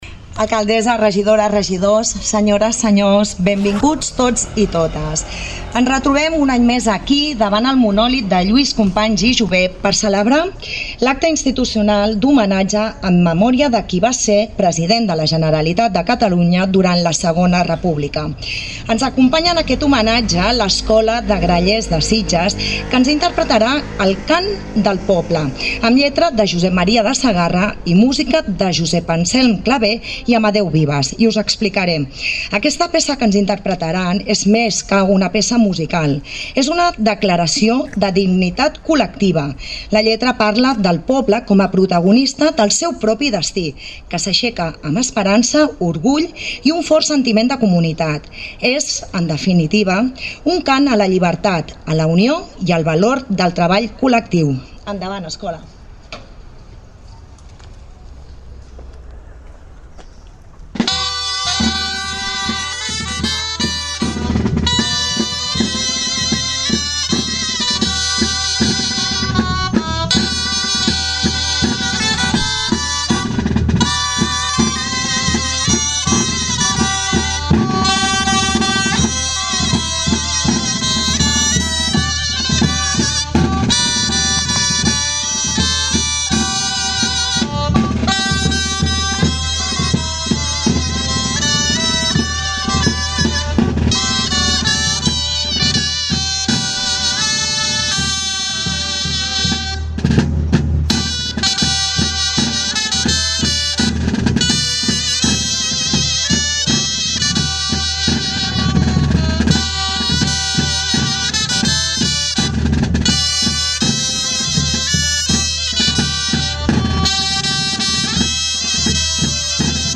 davant del monòlit que Sitges li va dedicar al carrer amb el seu nom, en el tradicional homenatge en l’aniversari del seu afusellament.
Diverses entitats i formacions polítiques, així com part de la corporació municipal, van fer ofrenes florals, en un acte en que l’Escola de Grallers de Sitges van interpretar el Cant del Poble i els Segadors, i que va acabar amb unes paraules de l’alcaldessa de Sitges, Aurora Carbonell.